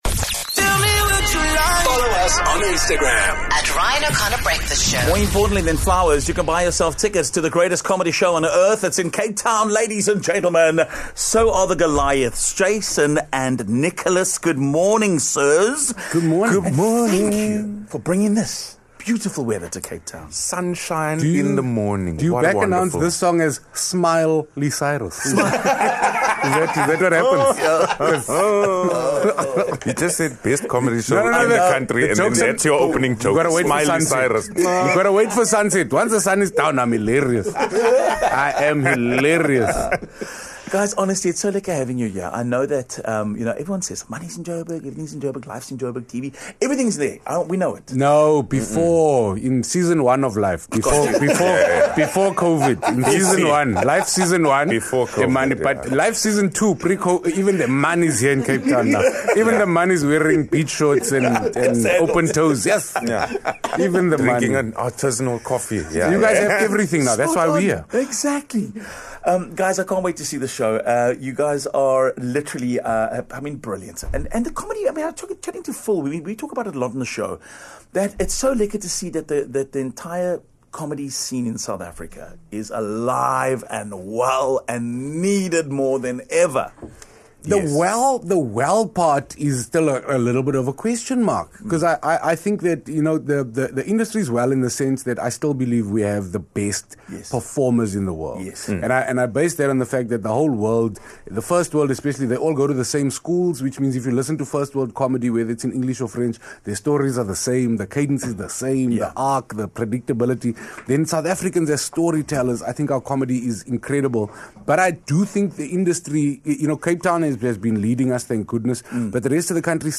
They joined us in studio ahead of their shows at the Protea Hotel Fire and Ice by Marriott.